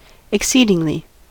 exceedingly: Wikimedia Commons US English Pronunciations
En-us-exceedingly.WAV